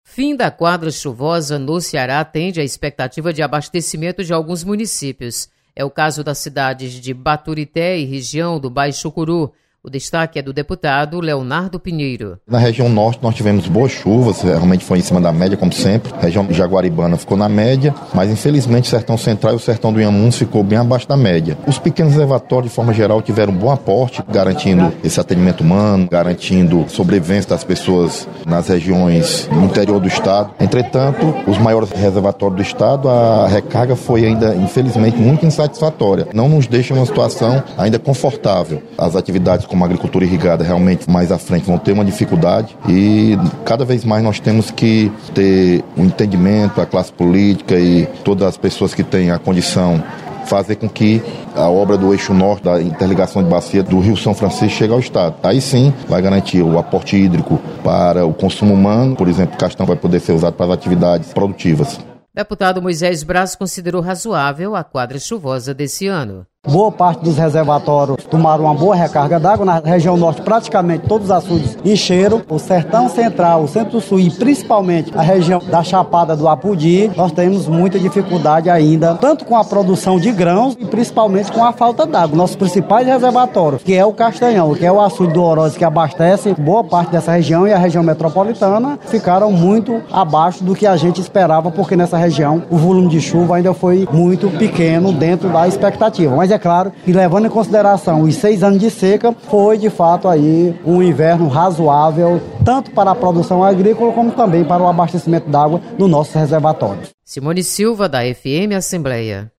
Deputados comentam sobre reserva hídrica do estado.